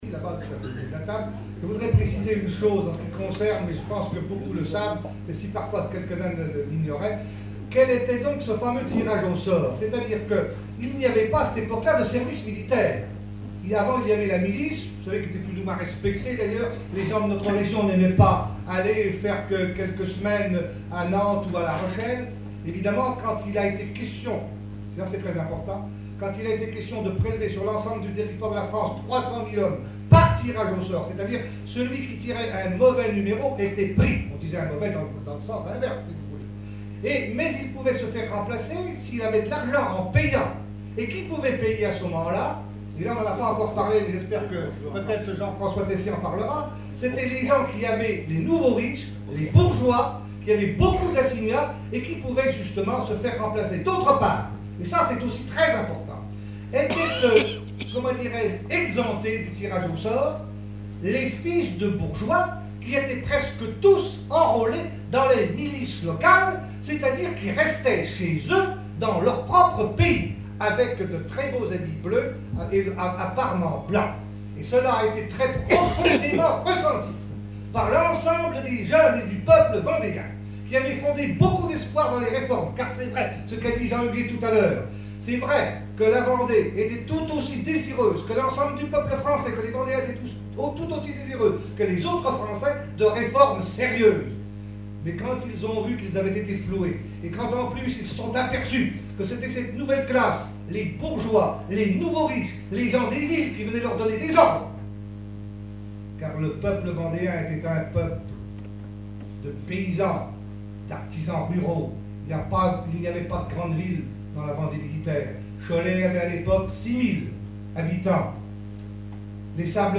conférence au Vasais
Catégorie Témoignage